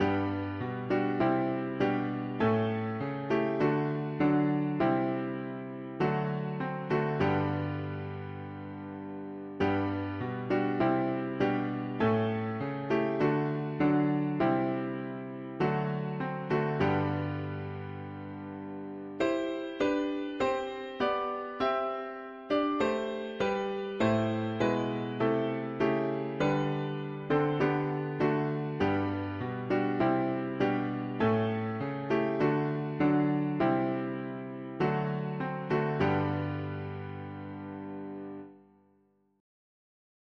Soft the drowsy hours are cree… english secular evening 4part chords
Audio (MIDI)
Key: G major